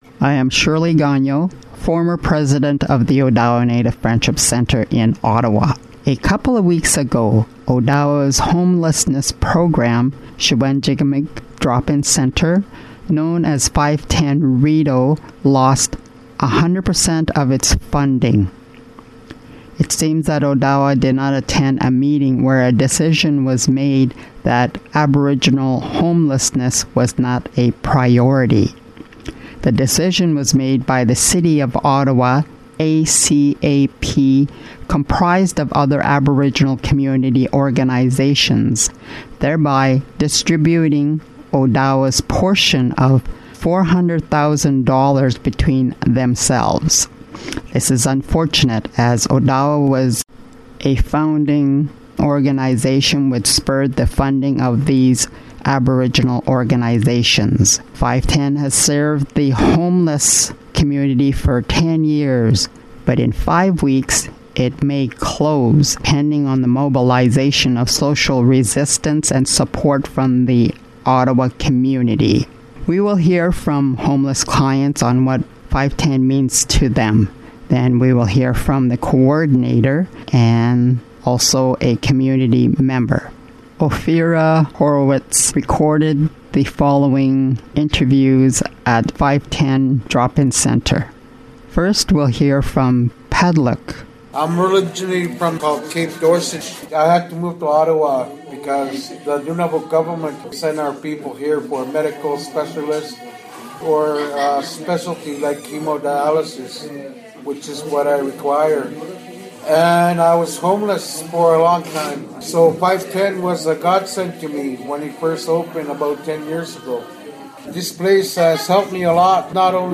Recording Location: Ottawa, Ontario
Type: News Reports